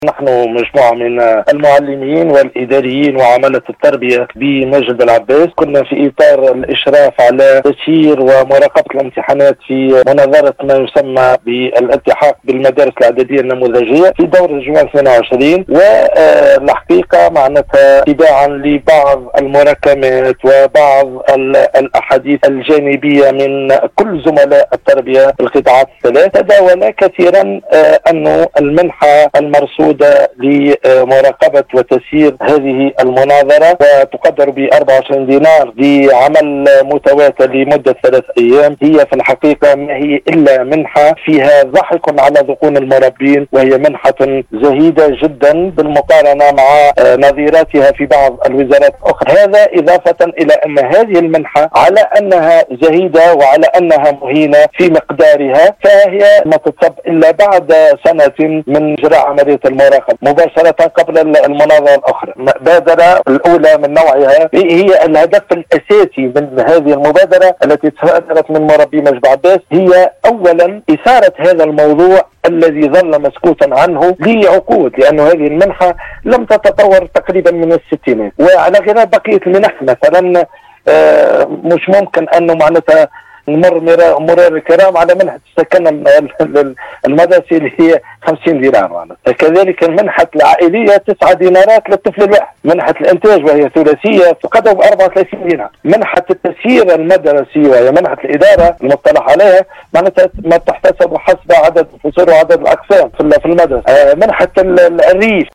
أثناء تدخّله ببرنامج القصرين و أحوالها اليوم الإثنين 27 جوان 2022 ،  أنّ مجموعة من الإطار التّربوي من معلّمين و إداريين و عملة ، المشرف على مراقبة مناظرة الإلتحاق بالمداري الإعداديّة النّموذجيّة دورة  جوان 2022 بالمركز الكتابي المفتوح بالمعهد الثانوي ماجل بلعباس 2  ، إذ يعلنون تمسكهم بالقيام بدورهم المضبوط بالقوانين الجاري بها العمل من مراقبة امتحانات و تسييرها ، فإنّهم يرفضون رفضا قاطعًا الحصول على منحة مراقبة مهينة تصرف بعد سنة  و تقدّر بـ 24 دينار .